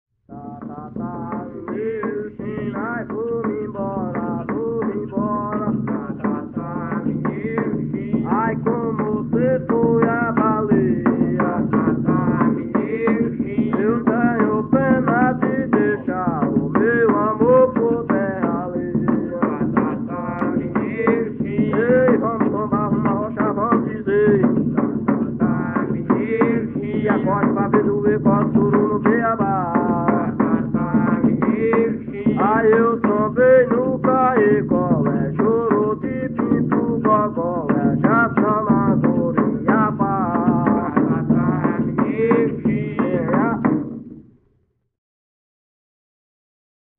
Coco embolada -""Tá tá tá, mineiro china""